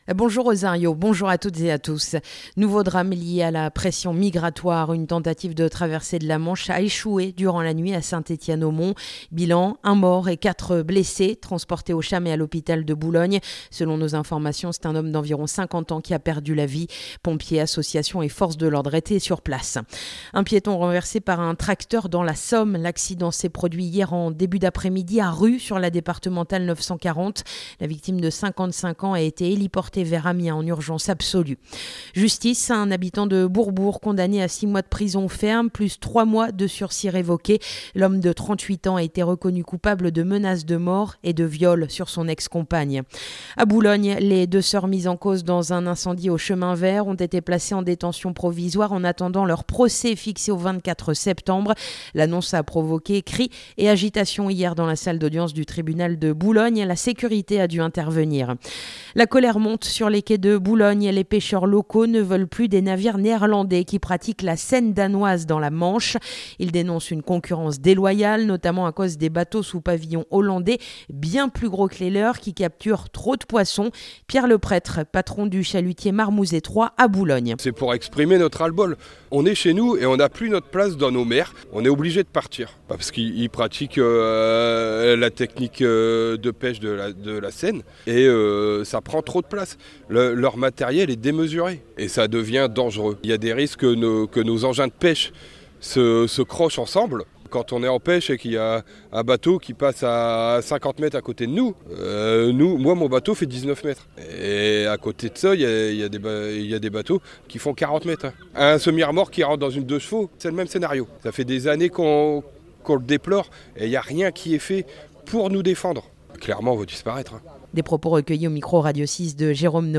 Le journal du samedi 26 juillet